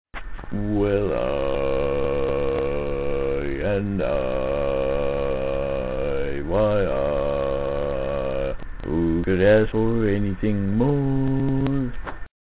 Key written in: D♭ Major
Each recording below is single part only.
Other part 1: